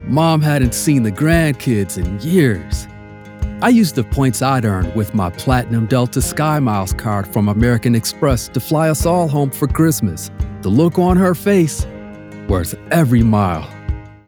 American Express Delta Skymiles - Caring, Emotional, Empathetic
North American, African American, Southern, Eastcoast
I record projects using the Focusrite Scarlet Solo, CM25 MkIII condenser mic and REAPER DAW.